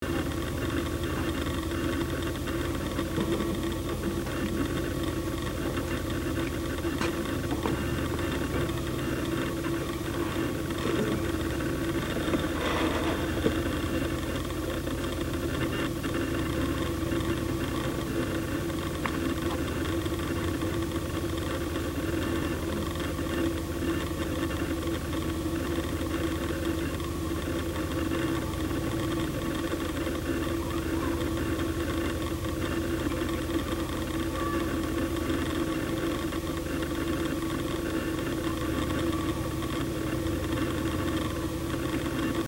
computer-hard-drive-access-fan-click-62422.mp3